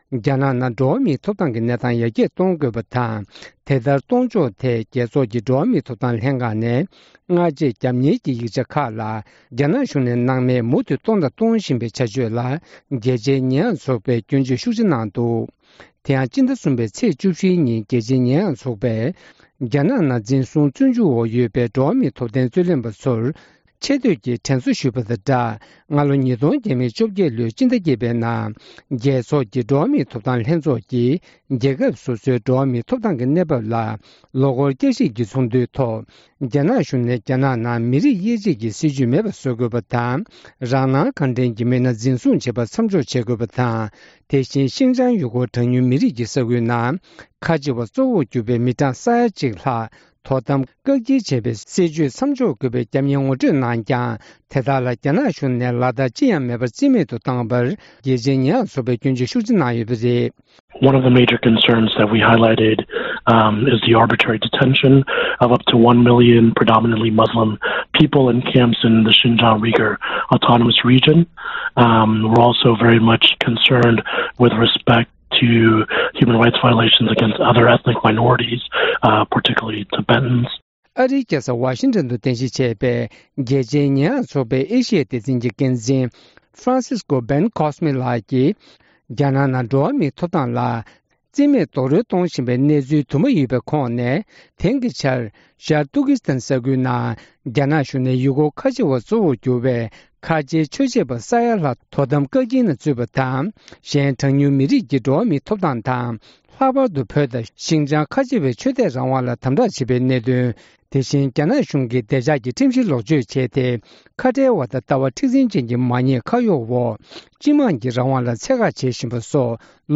བཅར་འདྲི་ཕྱོགས་བསྒྲིགས